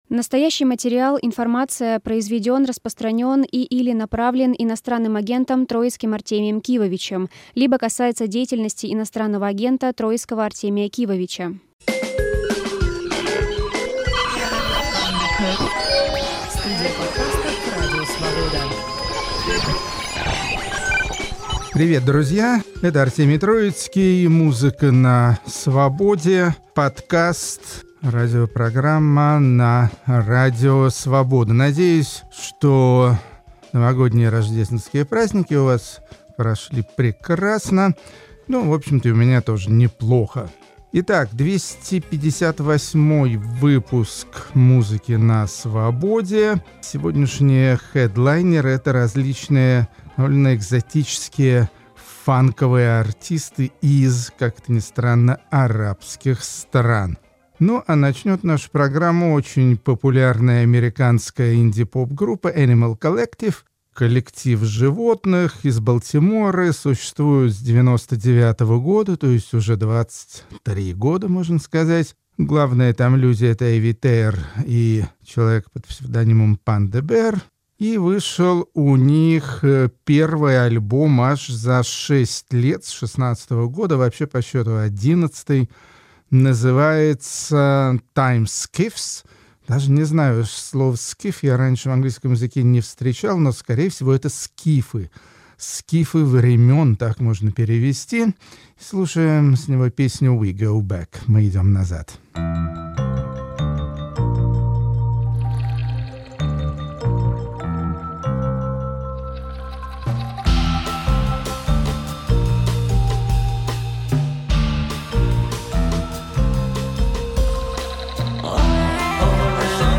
Banner - inoagent foreign banner Troitsky Двести пятьдесят восьмой выпуск подкаста и радиопрограммы "Музыка на Свободе" посвящён арабским исполнителям, популярным в своих странах примерно полвека назад. Если бывает в мире поп-музыки экзотика, то вот это как раз она.